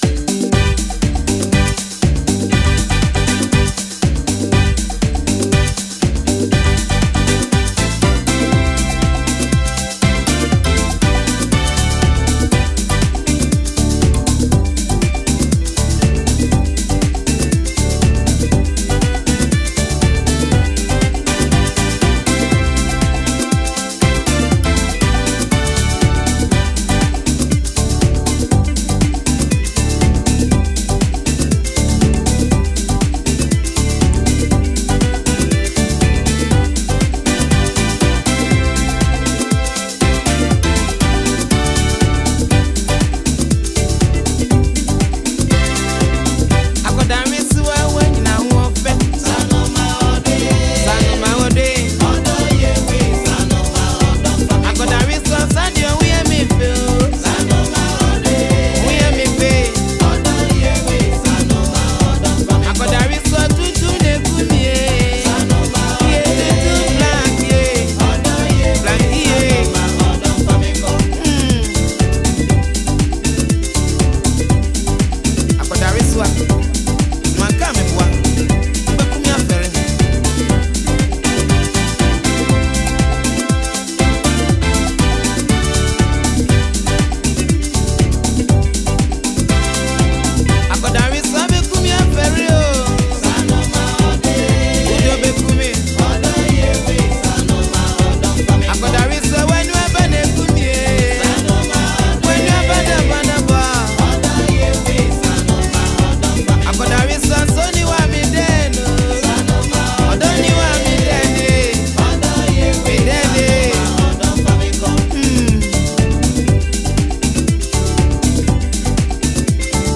Highlife
The legendary Ghanaian highlife musician